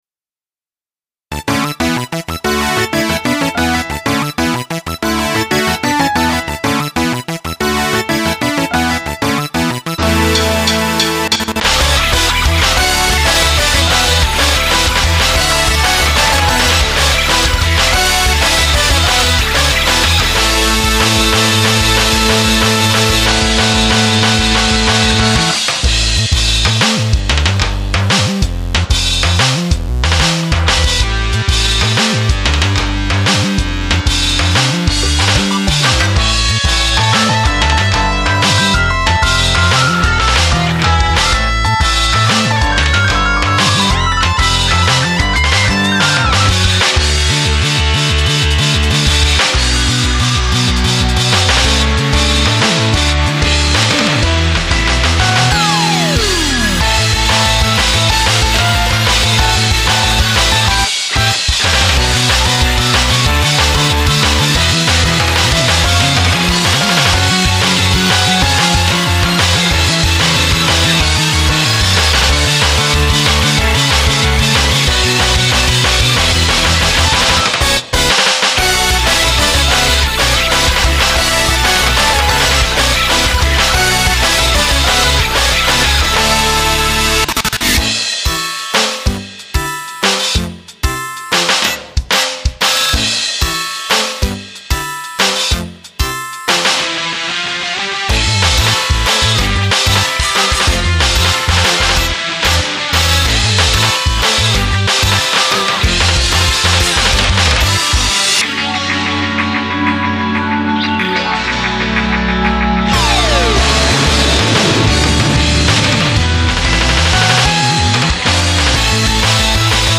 Vocaloid (ニコニコ動画マイリストへ)